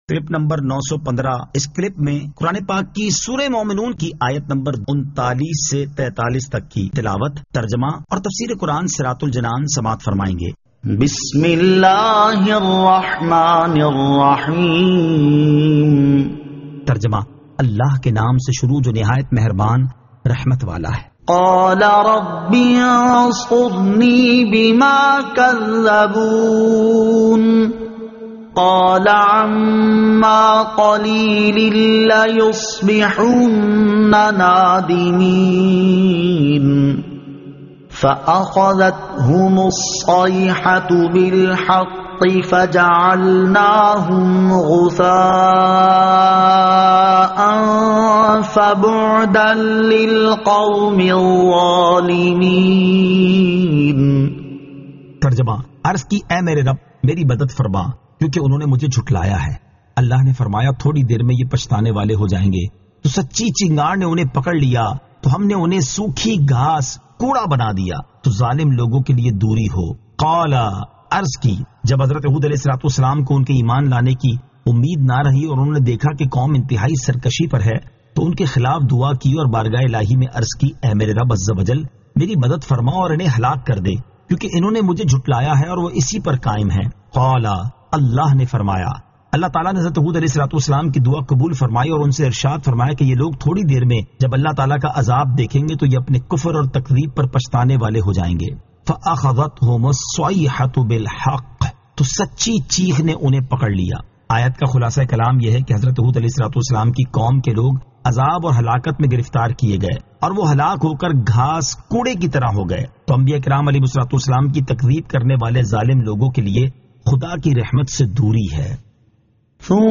Surah Al-Mu'minun 39 To 43 Tilawat , Tarjama , Tafseer